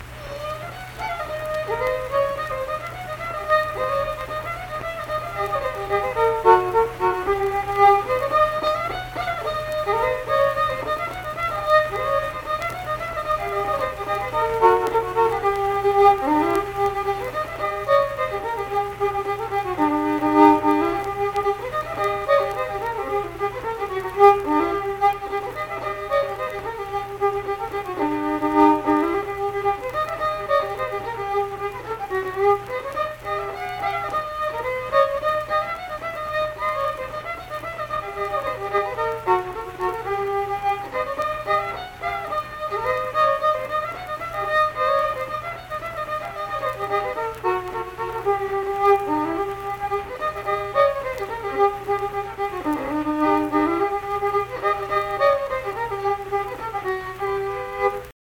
Unaccompanied fiddle music
Instrumental Music
Fiddle
Pleasants County (W. Va.), Saint Marys (W. Va.)